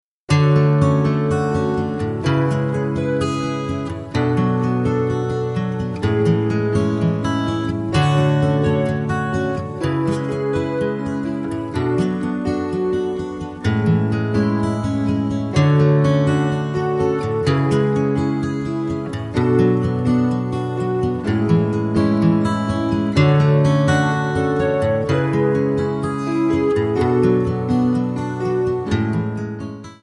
Backing track files: 1970s (954)
Buy Without Backing Vocals